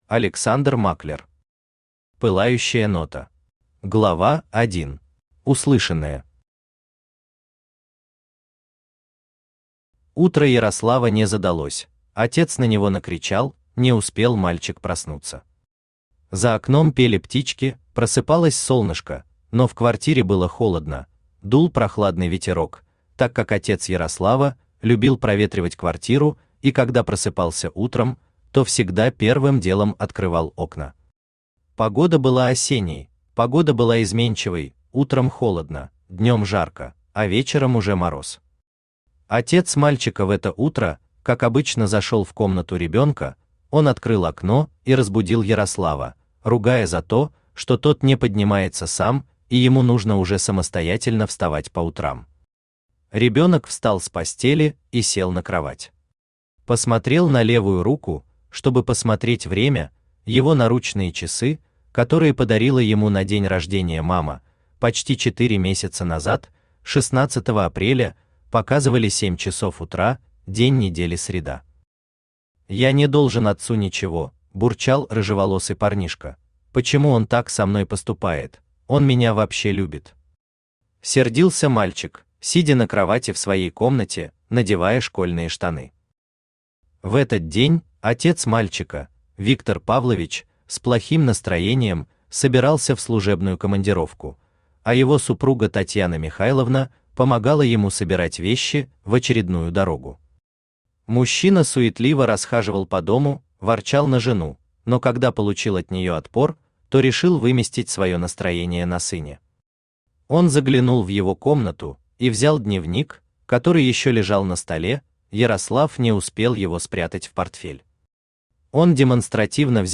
Аудиокнига Пылающая нота | Библиотека аудиокниг
Aудиокнига Пылающая нота Автор Александр Германович Маклер Читает аудиокнигу Авточтец ЛитРес.